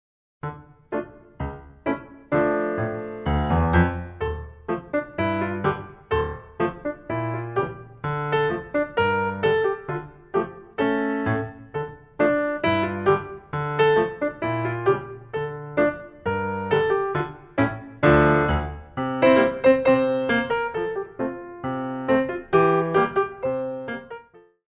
Frappé